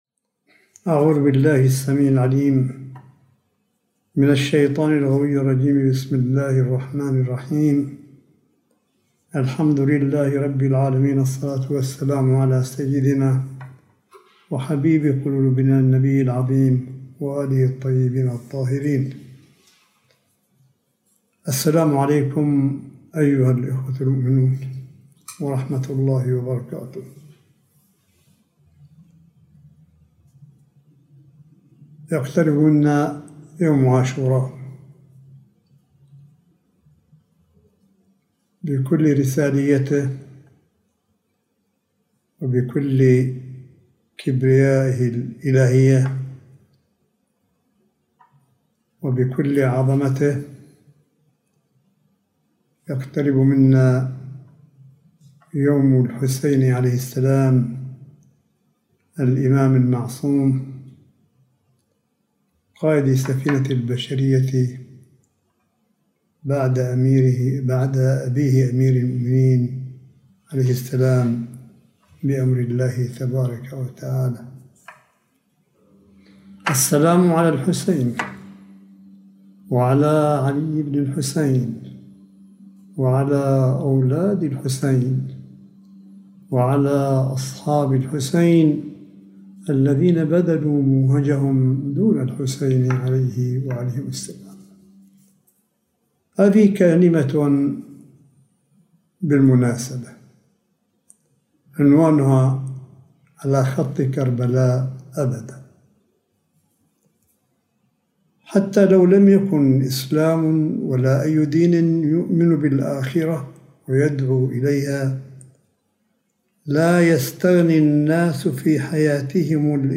كلمة آية الله قاسم “على خط كربلاء أبدًا” – محرم 1443هـ
كلمة سماحة آية الله الشيخ عيسى أحمد قاسم التي ألقاها في الفعالية التي نظمتها مؤسسة عاشوراء الدولية خلال موسم عاشوراء 1443هـ، والتي كانت تحت عنوان (على خطّ كربلاء أبداً)